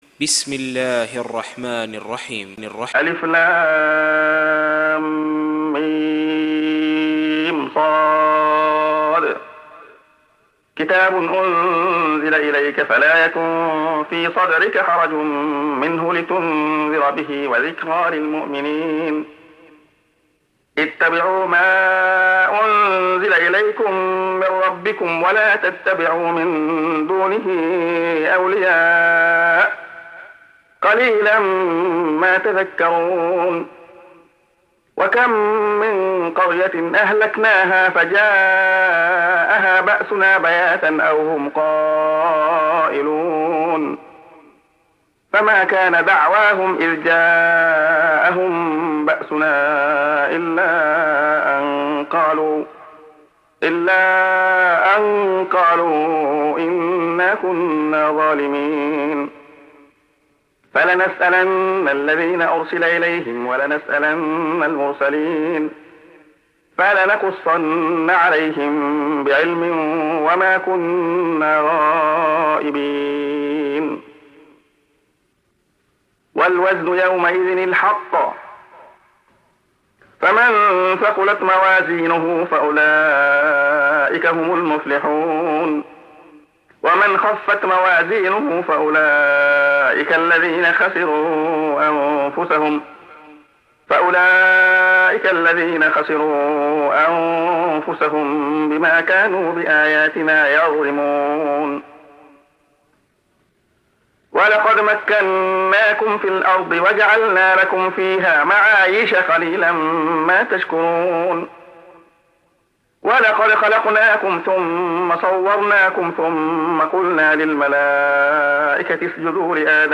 سُورَةُ الأَعۡرَافِ بصوت الشيخ عبدالله الخياط